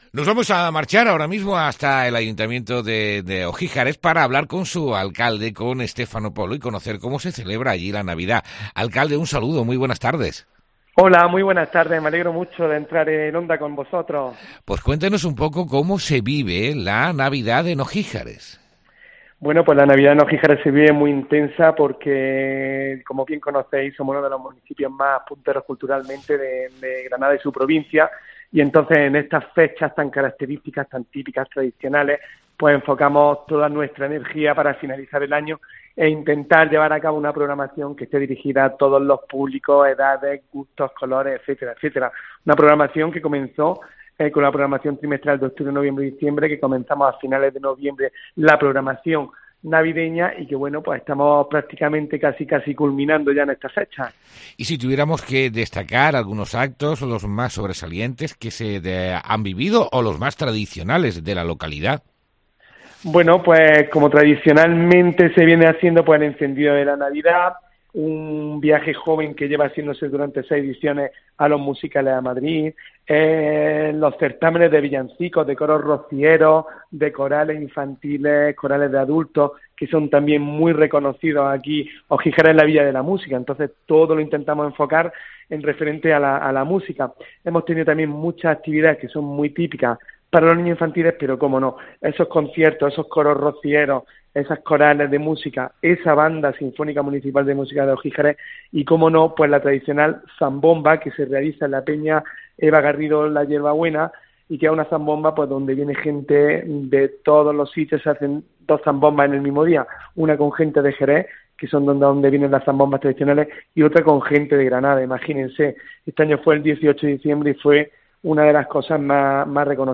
El alcalde de Ogíjares nos cuenta cómo están viviendo la Navidad en esa localidad